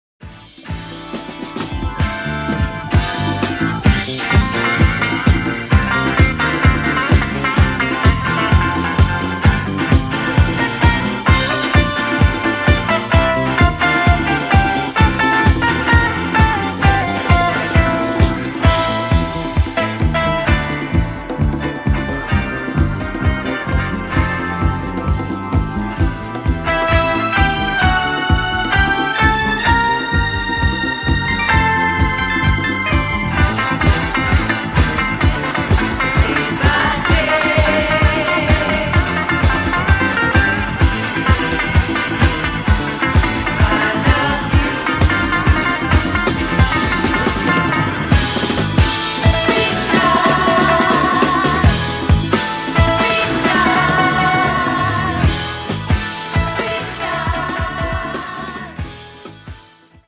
Underground break-beat fuelled disco funk